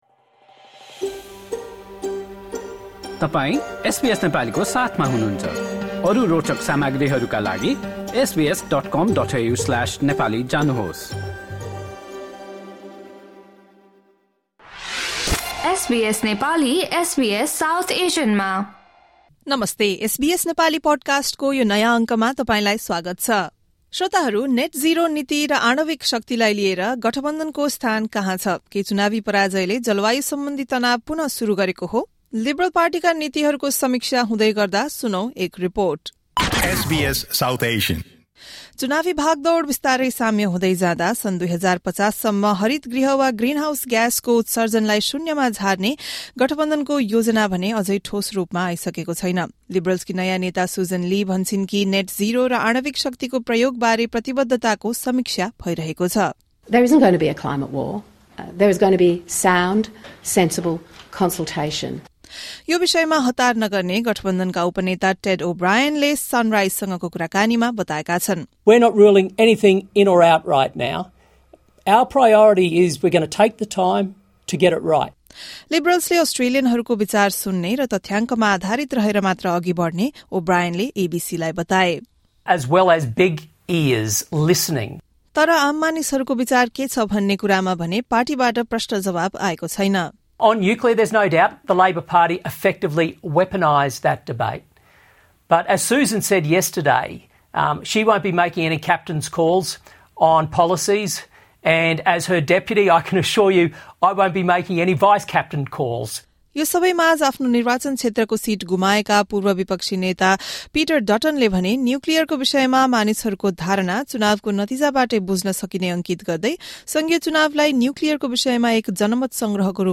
एक रिपोर्ट।